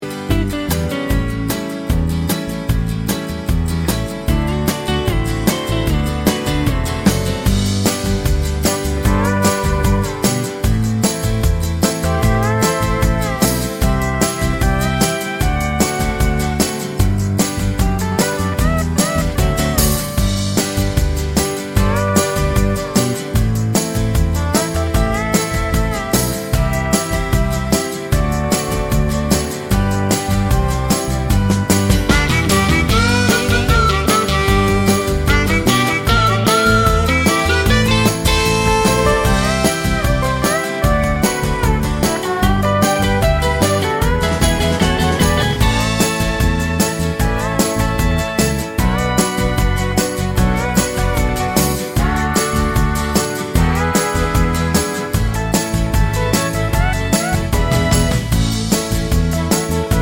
no Backing Vocals Country (Male) 2:21 Buy £1.50